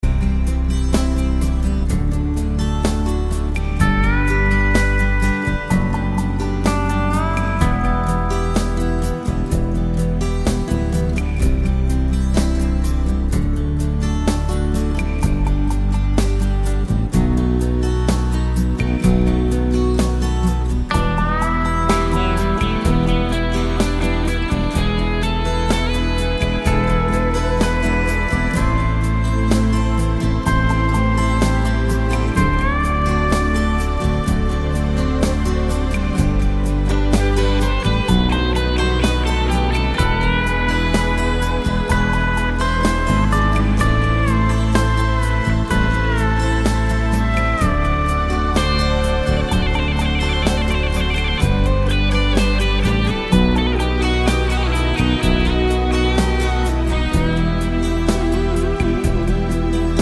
no Backing Vocals Easy Listening 5:01 Buy £1.50